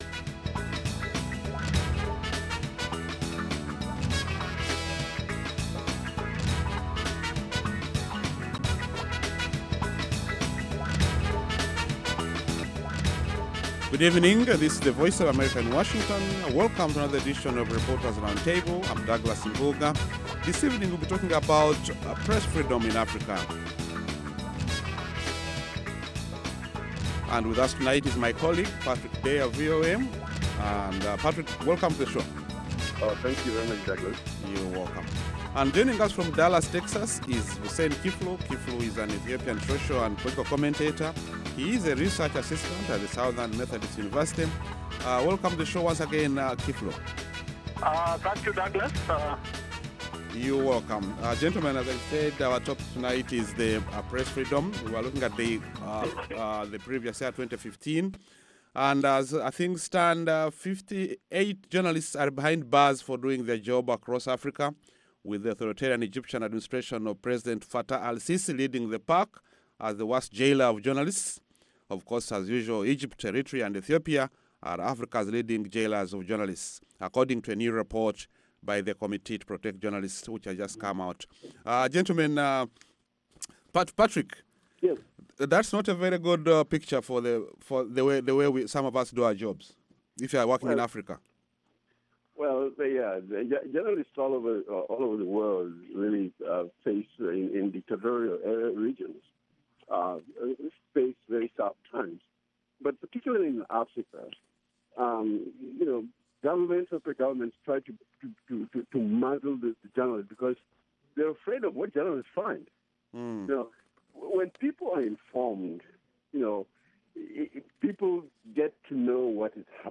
along with a lively panel of journalists, who analyze the week’s major developments in Africa.